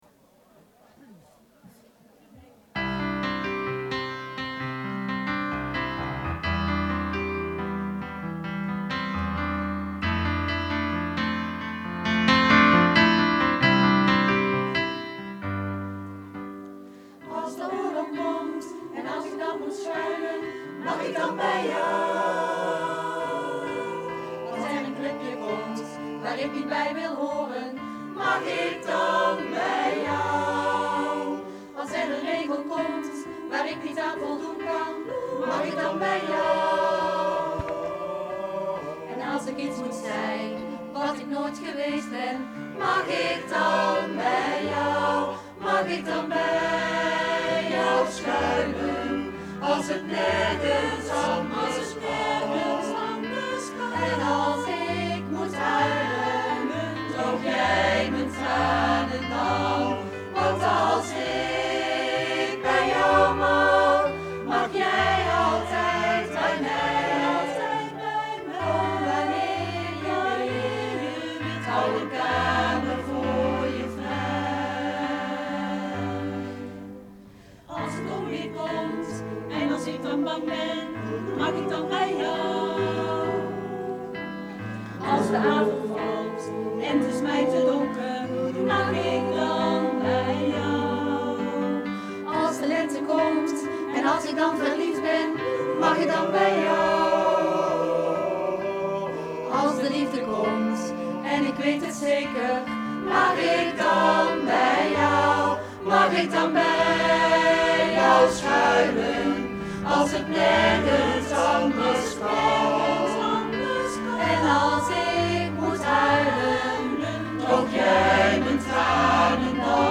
Hieronder kun je de nummers afspelen die we tijdens het openlucht optreden bij recreatieplas de Langspier in Boxtel hebben gezongen. Je hoort soms de wind.